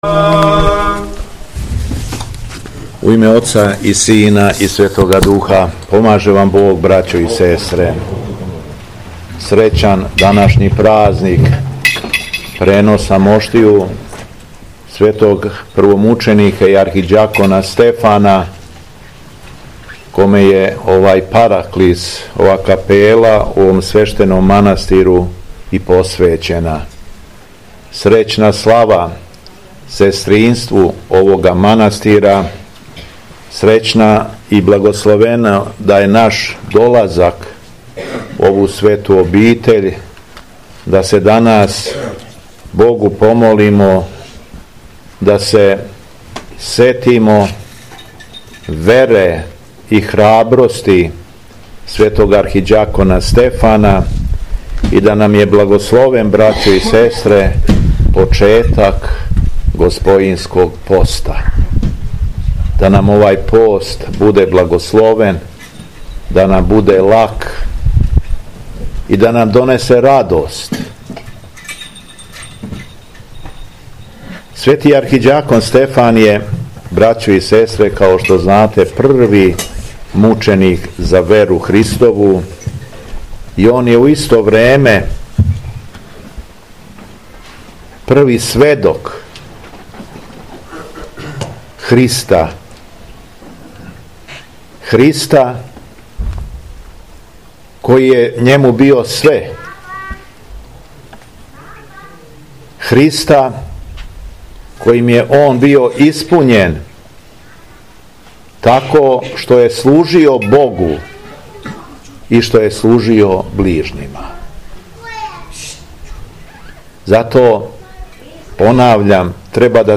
АРХИЈЕРЕЈСКА ЛИТУРГИЈА У МАНАСТИРУ НИКОЉЕ - Епархија Шумадијска
Беседа Његовог Високопреосвештенства Митрополита шумадијског г. Јована
Након прочитаног зачала из Светог Јеванђеља Високопреосвећени се обратио верном народу рекавши: